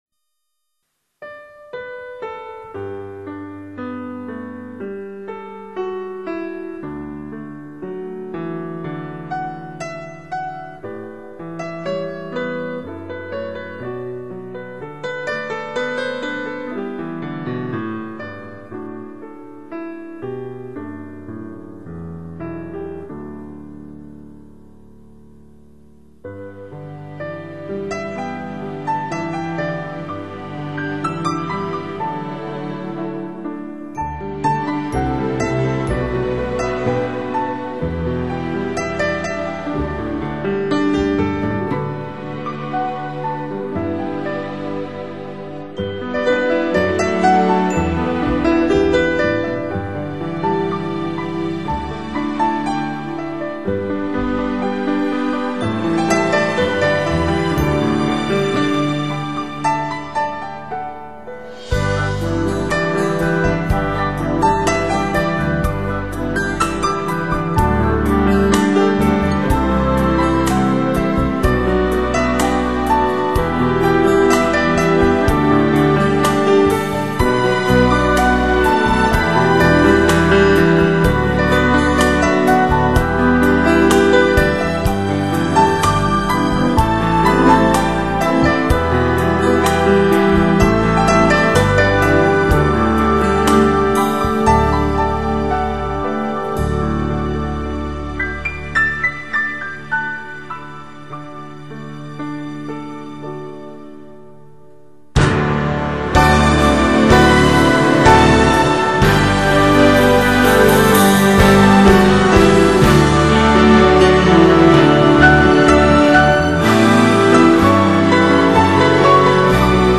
专辑歌手:纯音乐
钢琴演奏